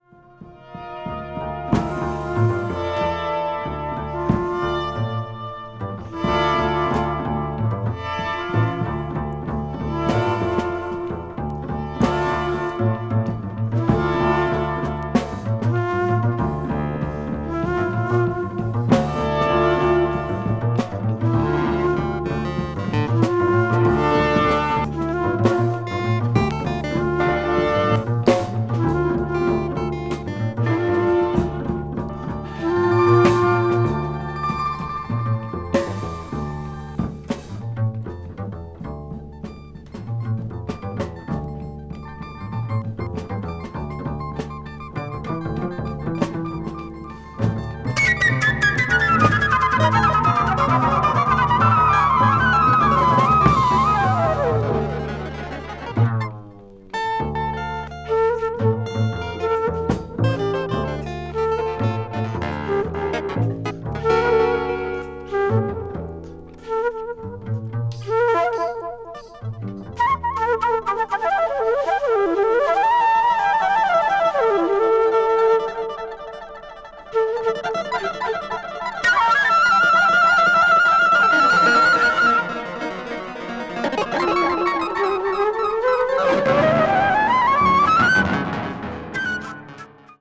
media : EX/EX-(わずかにチリノイズが入る箇所あり,B2最終:軽いチリノイズが入る箇所あり)
avant-jazz   contemporary jazz   ethnic jazz   free jazz